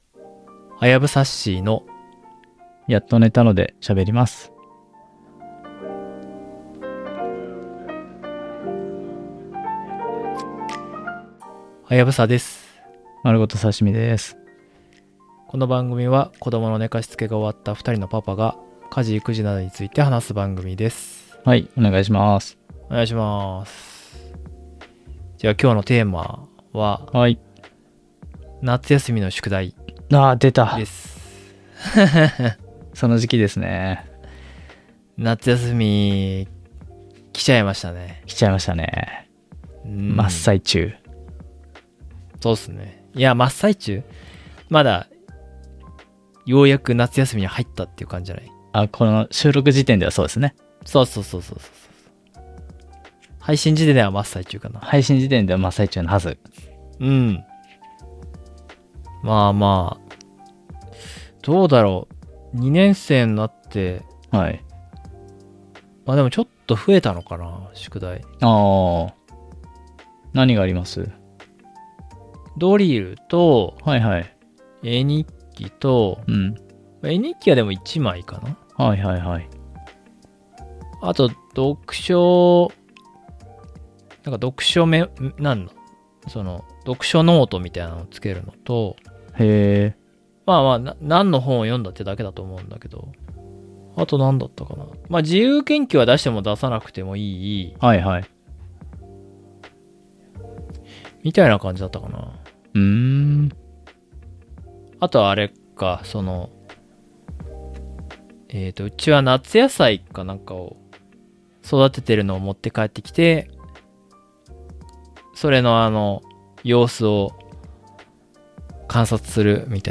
この番組は子どもの寝かしつけが終わったふたりのパパが、家事育児などについて話す番組です。